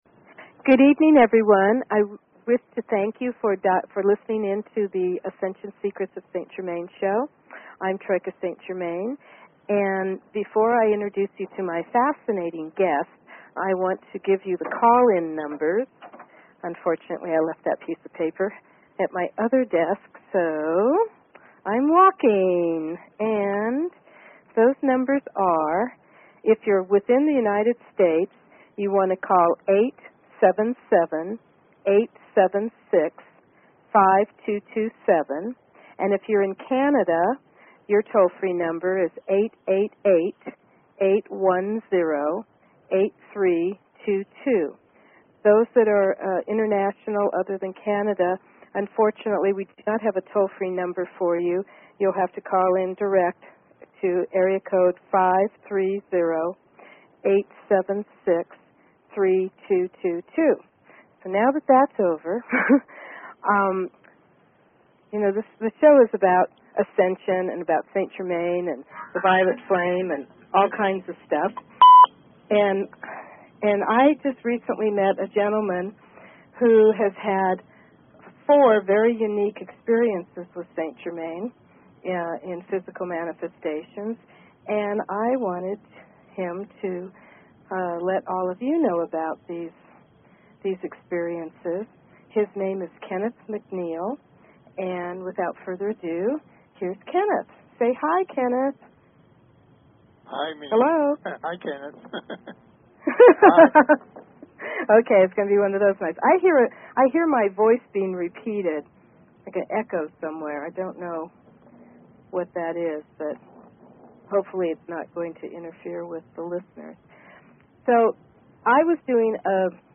Talk Show Episode, Audio Podcast, Ascension_Secrets_of_St_Germain and Courtesy of BBS Radio on , show guests , about , categorized as